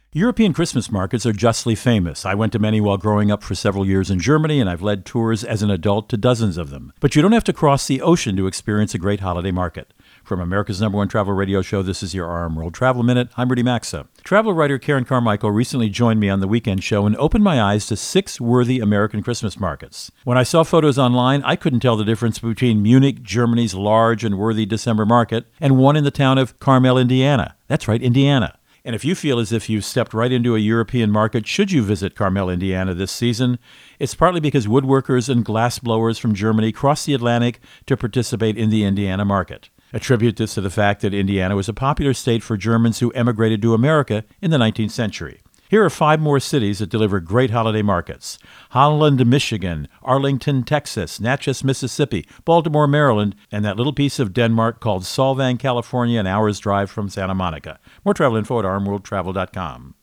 Co-Host Rudy Maxa | Of course America has Great Christmas Markets